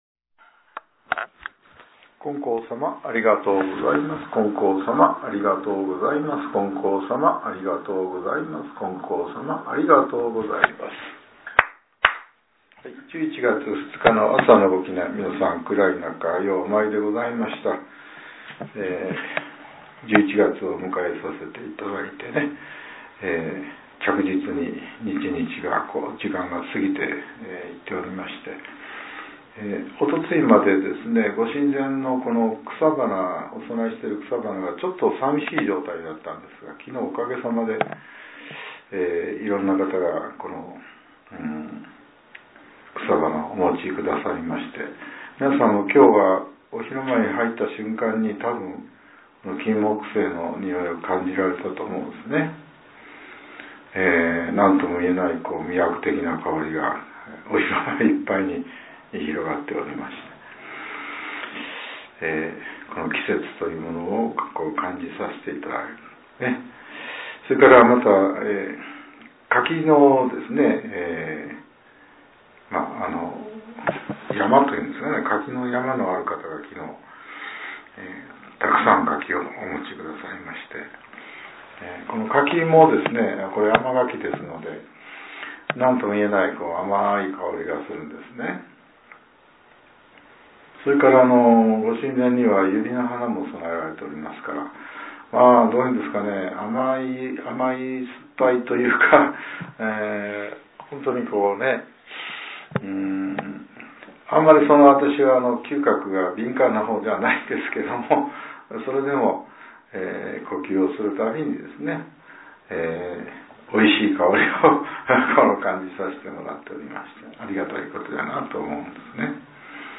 令和７年１１月２日（朝）のお話が、音声ブログとして更新させれています。